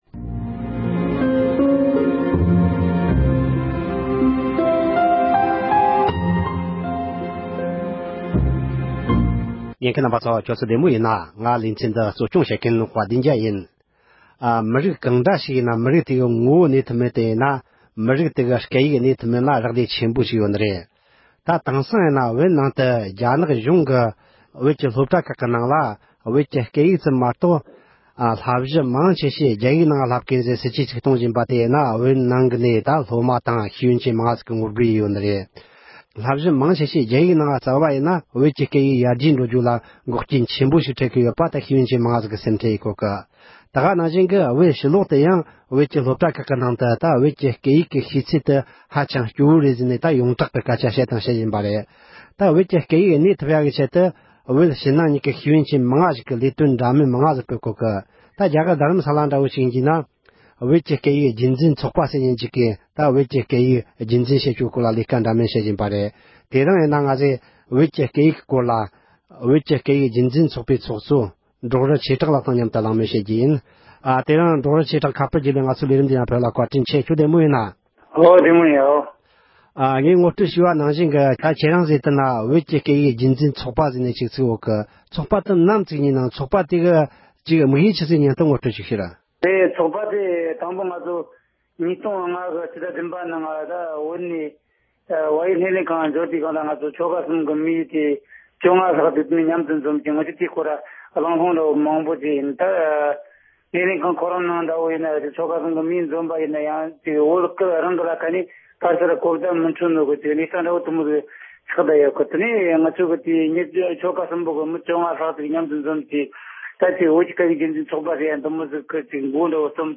བོད་ཀྱི་སྐད་ཡིག་གི་གནས་སྟངས་དང་རྒྱུན་འཛིན་བྱེད་ཕྱོགས་ཐད་གླེལ་མོལ་ཞུས་པ།